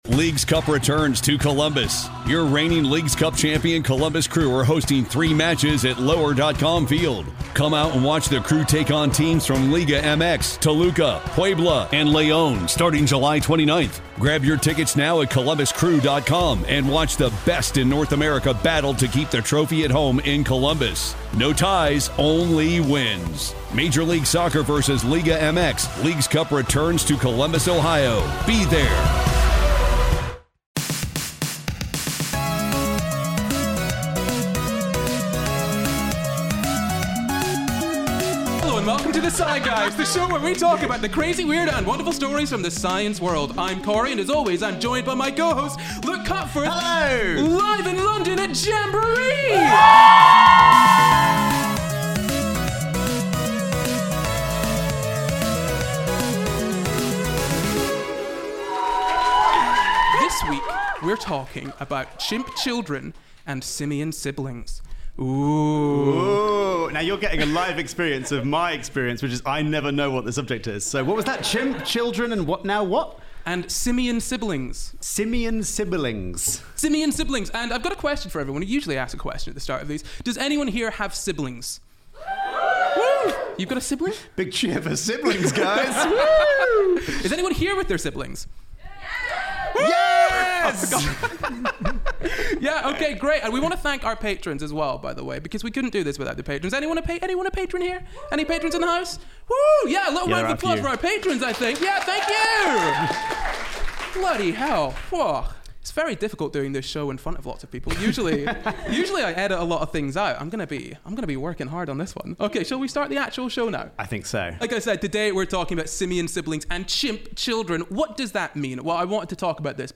The Ape & The Child (Live in London)